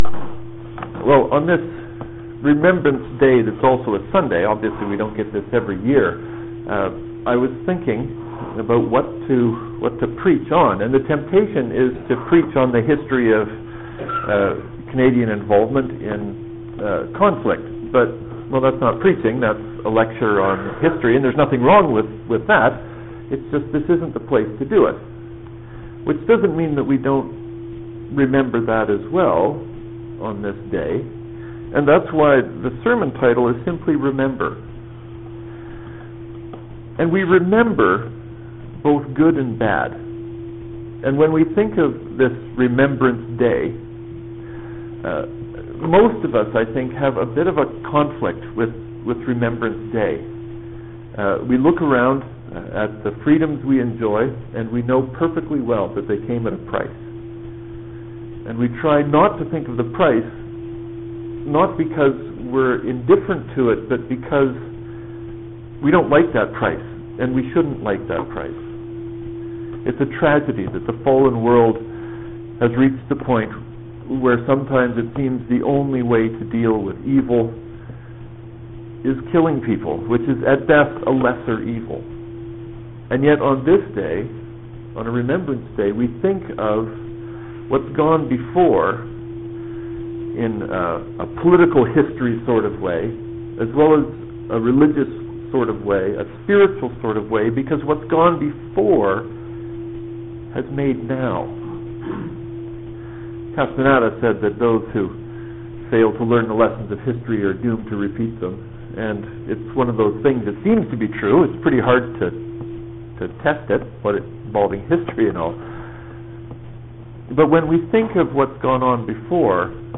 It is my contention that the most important thing for this day is to remember. Knox Presbyterian Remember Date: November 11, 2012 Liturgical Sunday: Twenty-Fourth Sunday after Pentecost RCL Scripture: Psalm 127; Ruth 3:1-5; 4:13-17; Hebrews 9:24-28; Mark 12:38-44 Sermon Title: Remember